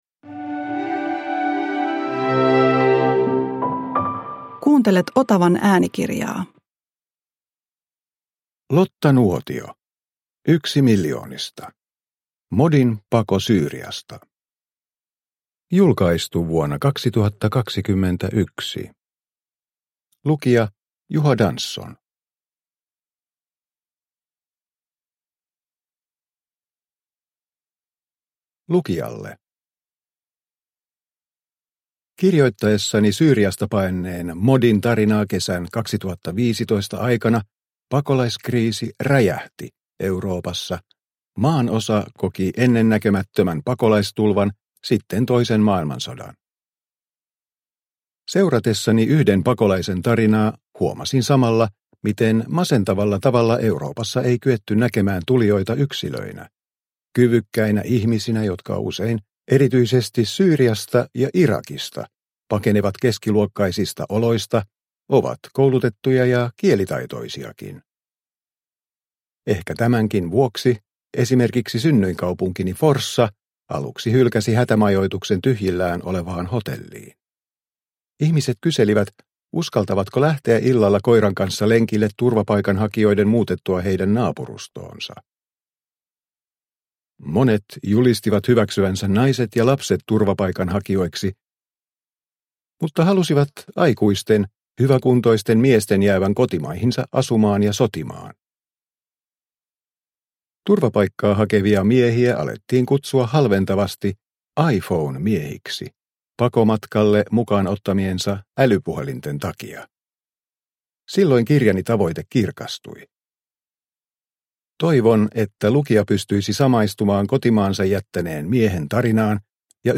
Yksi miljoonista – Ljudbok